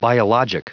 Prononciation du mot biologic en anglais (fichier audio)
Prononciation du mot : biologic